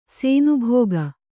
♪ sēnubhōga